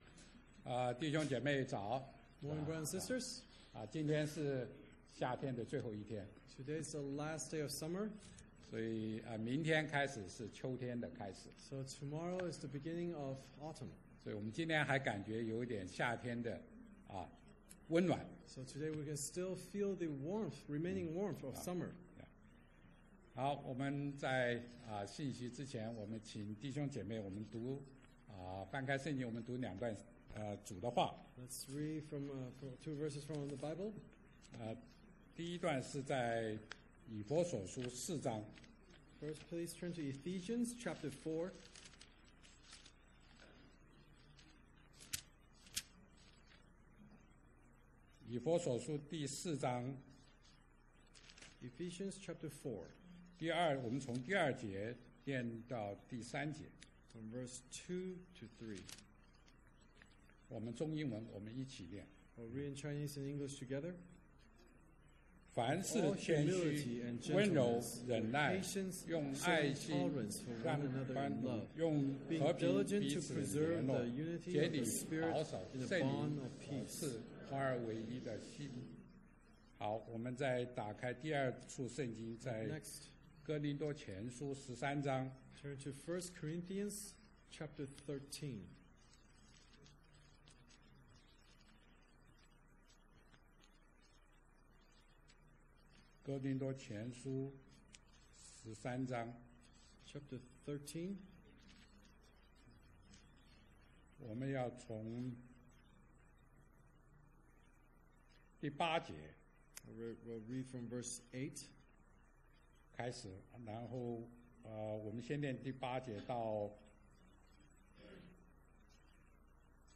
東區基督教會主日崇拜講道信息